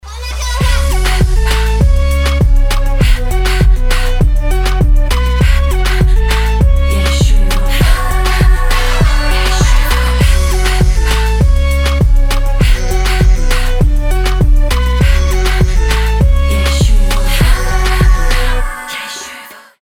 поп
Trap